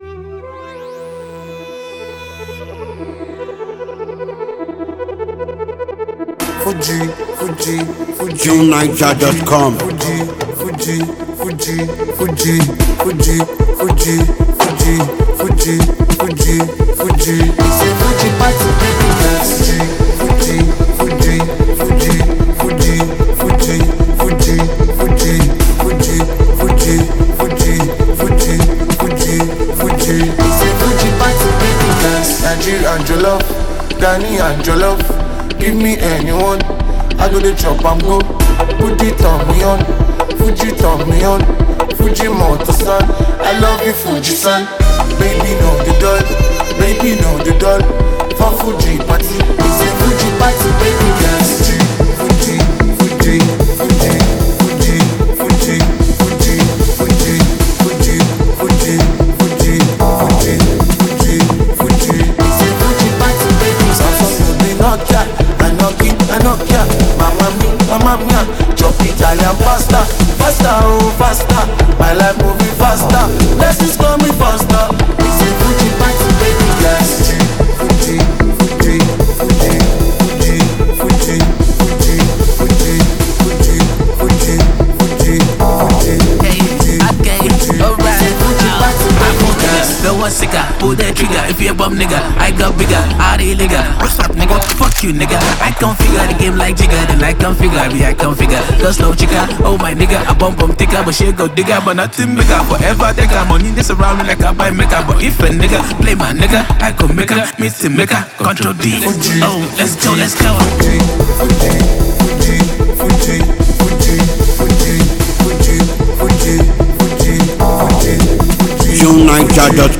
Add it to your relaxing music and you’ll thank us later.